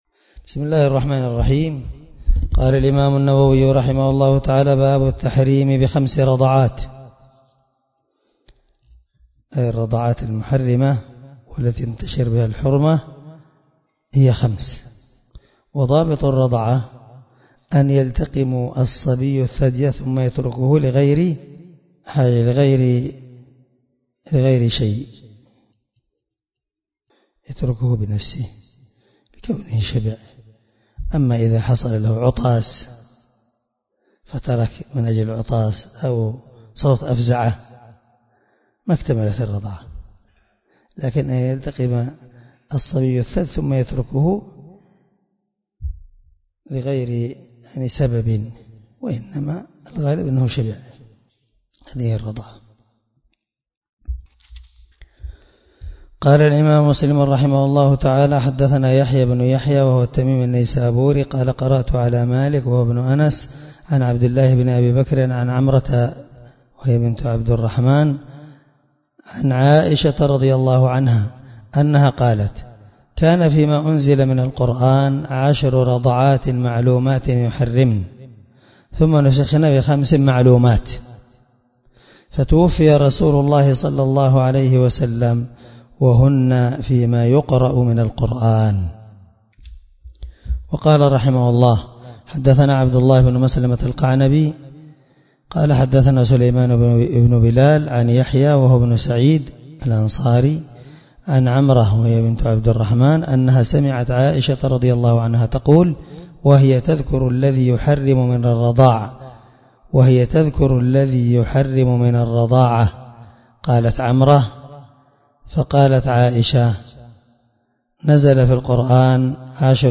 الدرس6من شرح كتاب الرضاع حديث رقم(1452) من صحيح مسلم